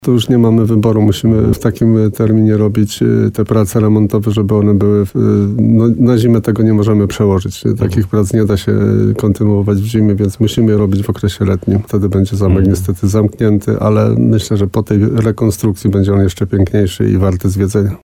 Jak mówił w programie Słowo za Słowo w radiu RDN Nowy Sącz wójt Rytra Jan Kotarba, zaplanowane prace muszą ruszyć.